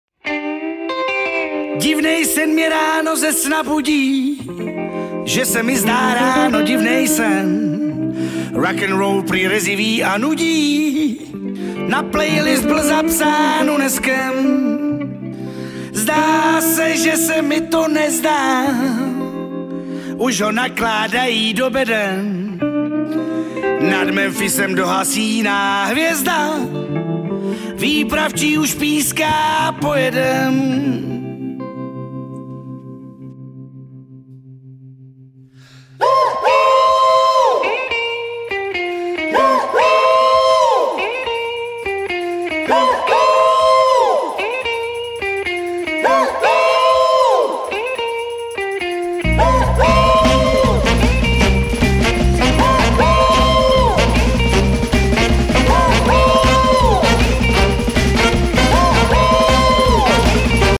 hosty na dechové nástroje.